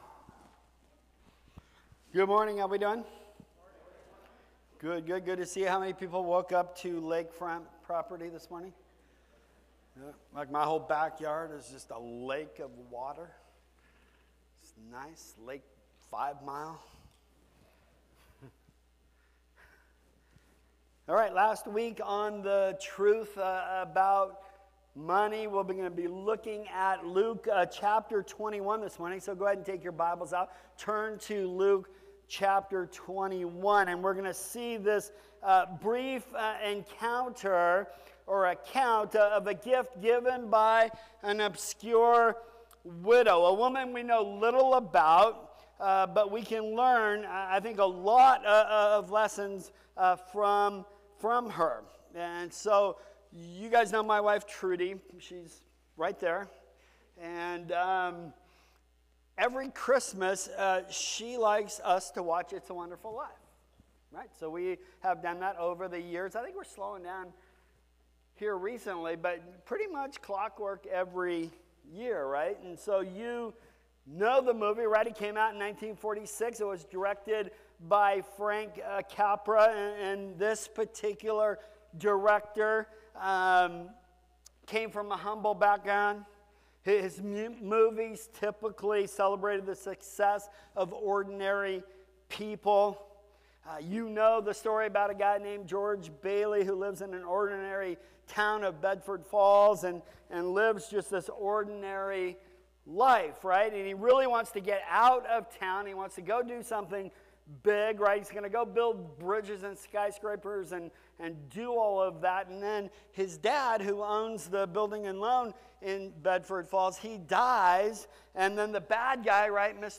Sermons | New Creation Fellowship